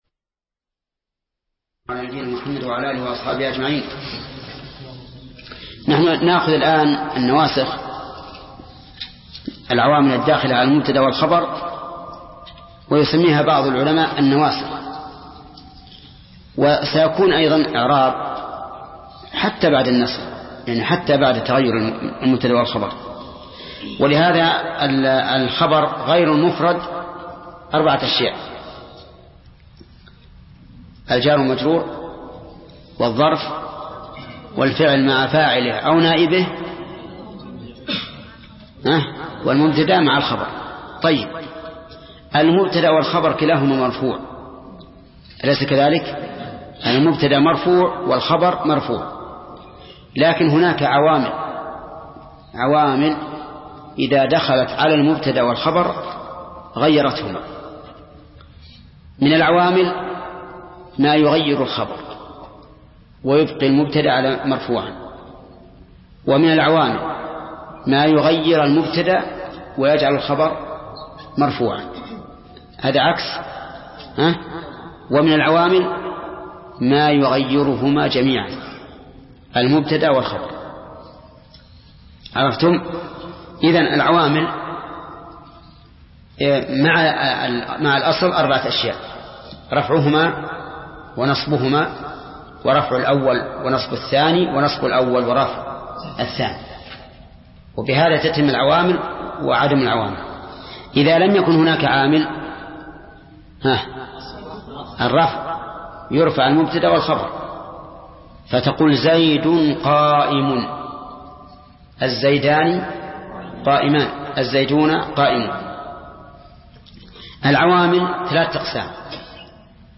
درس (13) : شرح الآجرومية : من صفحة: (265)، قوله: (نواسخ المبتدأ والخبر)، إلى إلى صفحة: (285)، قوله: (ظن وأخواتها).